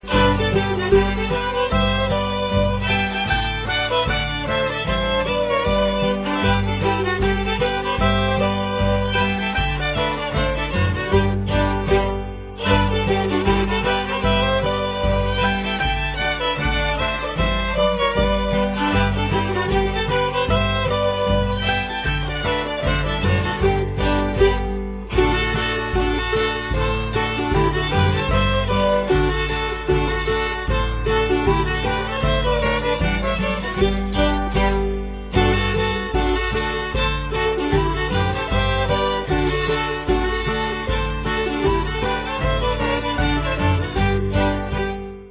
fiddles
mandolin
guitar
drums